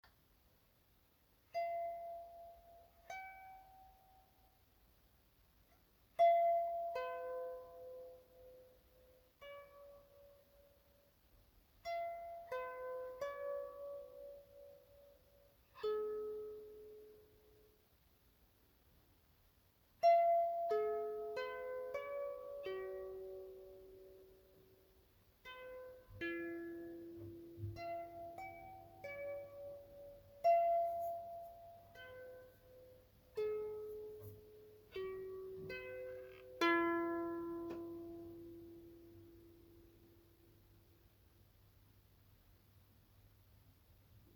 HS 5007 Harpschaal
Deze harpschaal heeft zeven harpsnaren die pentatonisch
en zeer geschikt voor meditatieve momenten.
dat het geluid van de harpsnaren op een mooie heldere